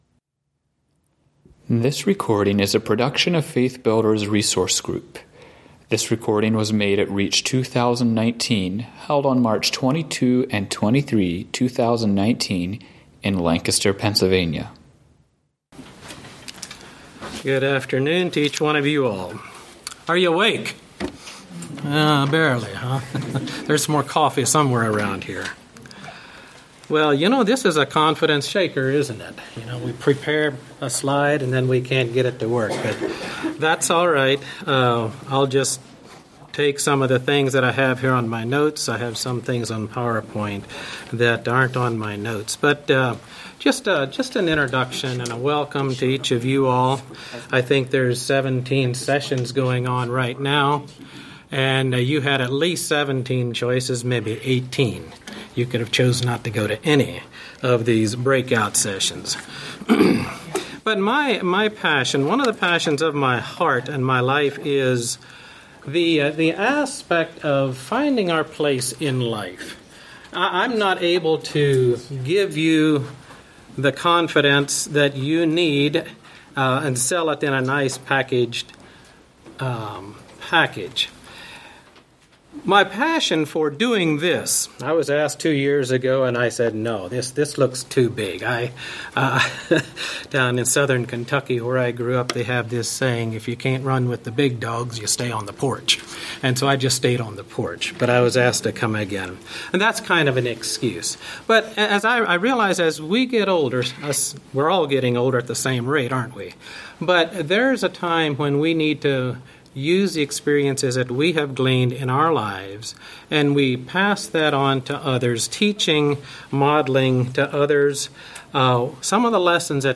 Home » Lectures » Living with Confidence in an Age of Indecision